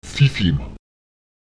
Lautsprecher cicem [ČTiTem] verdampfen, verdunsten (gasförmig werden)